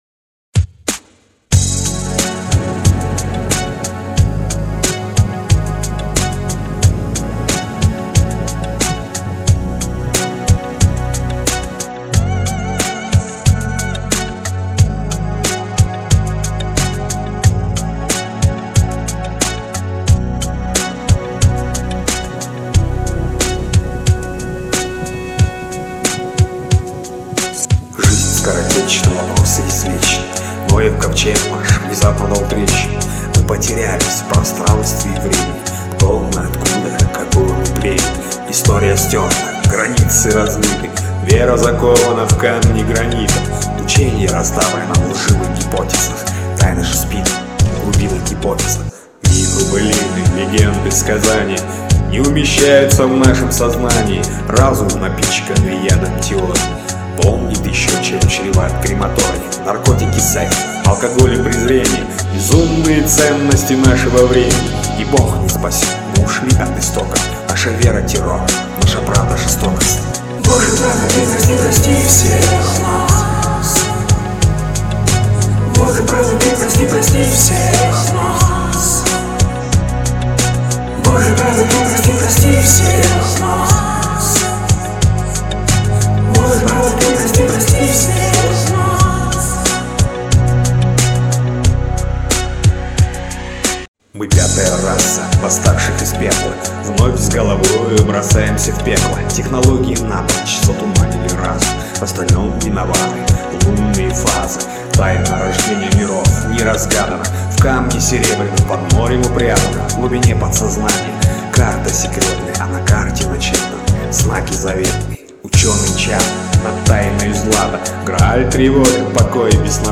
Студийная